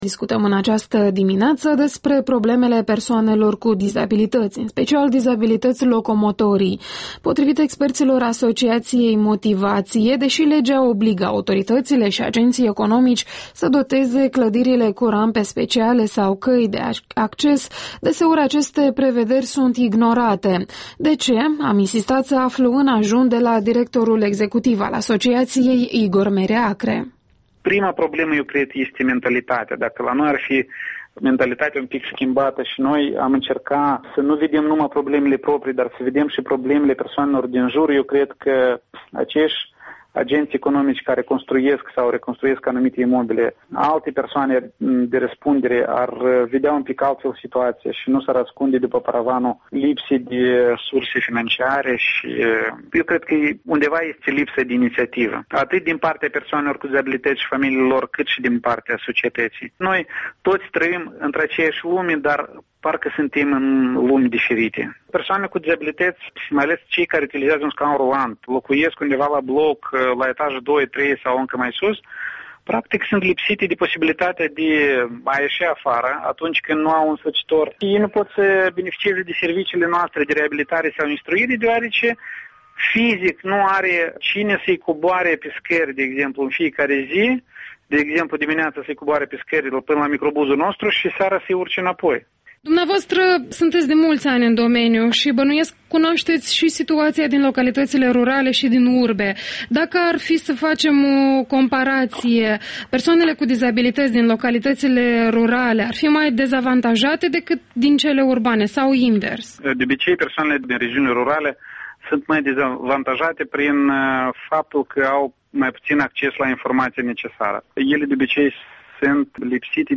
Interviul dimineții la EL